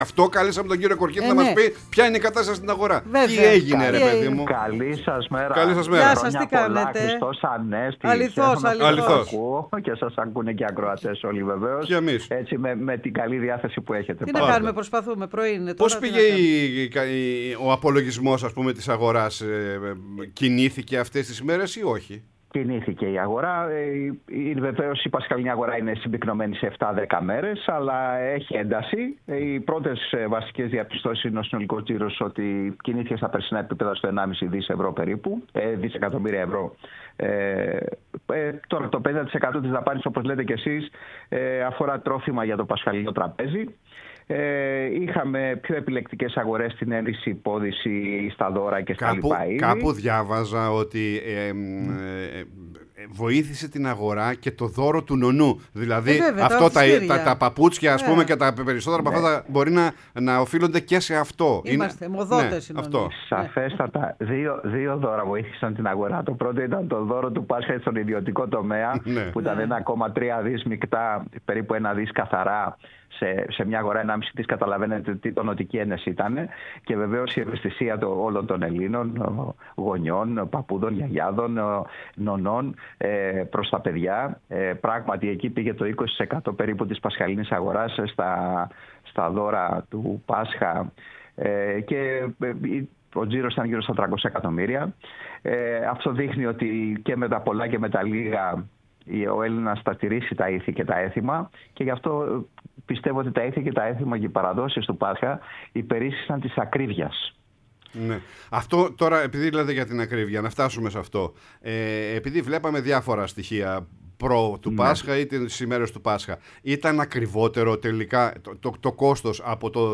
μίλησε στην εκπομπή «Πρωινή Παρέα»